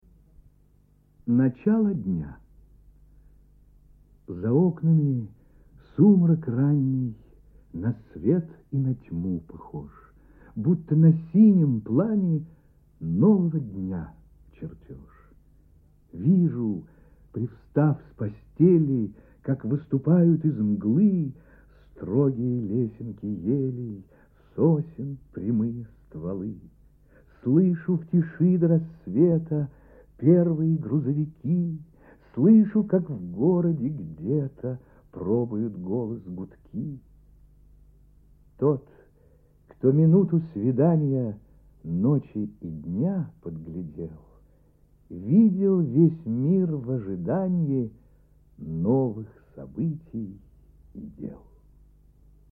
1. «С. Маршак – Начало дня (чит. В.Ларионов)» /